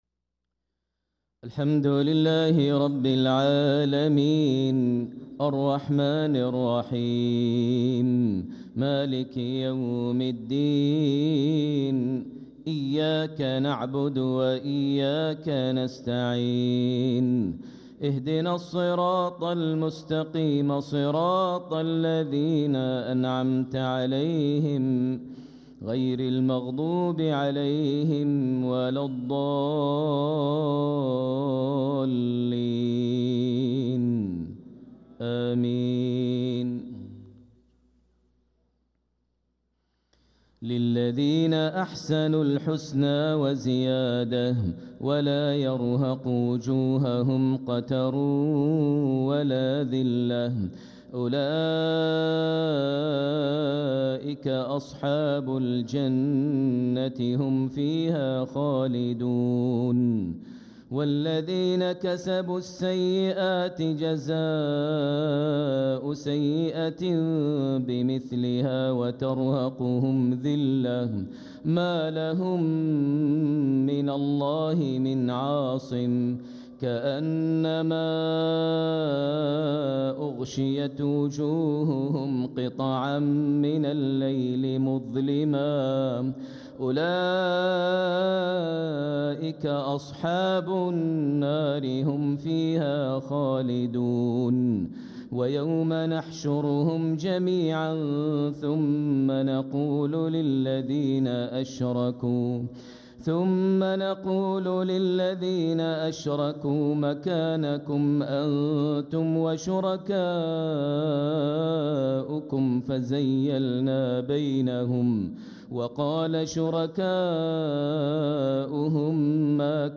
صلاة العشاء للقارئ ماهر المعيقلي 22 جمادي الأول 1446 هـ
تِلَاوَات الْحَرَمَيْن .